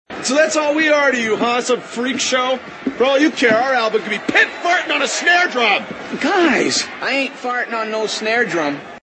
Fart on drum
Tags: Airheads clips Comedy Brendan Fraser Adam Sandler Movie